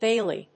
/ˈbeli(米国英語), ˈbeɪli:(英国英語)/